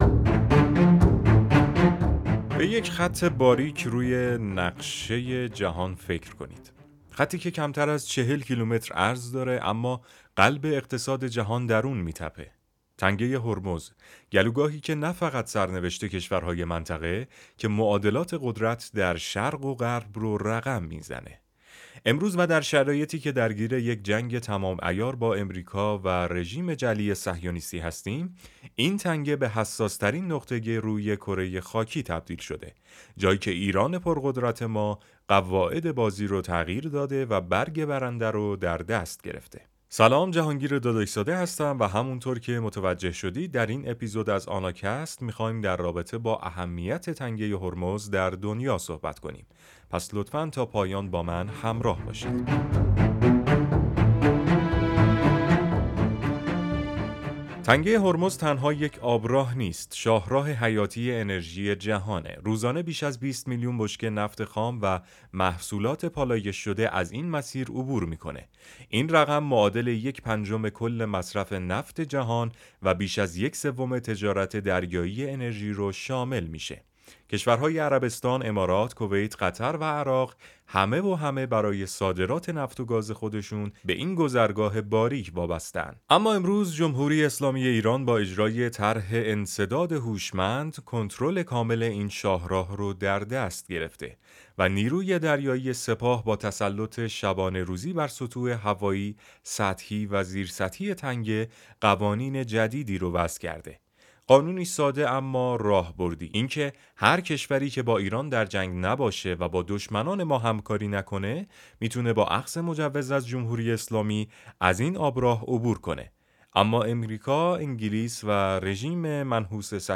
مستند